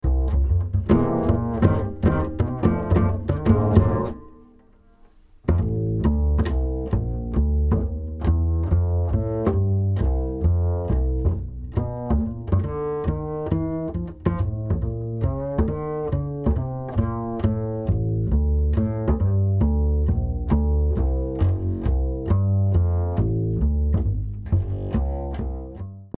fun, swinging blues head
overdubbed 4-bass arrangement